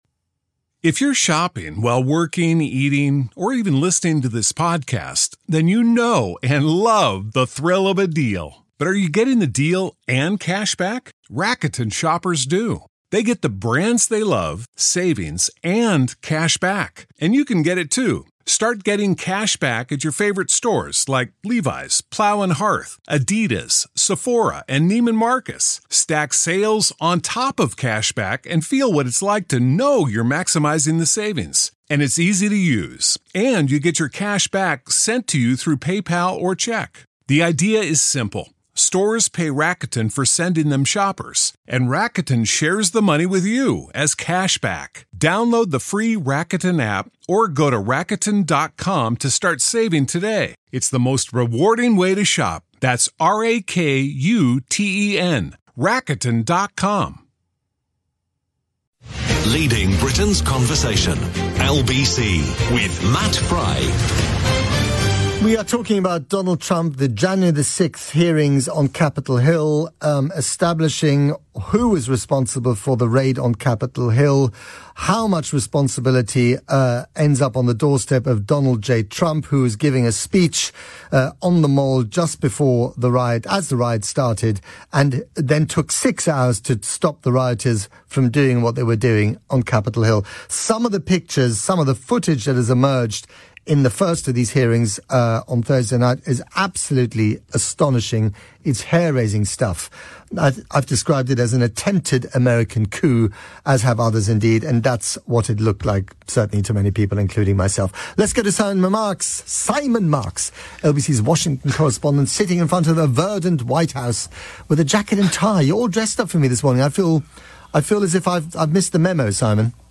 live chat with Matt Frei on the UK's LBC.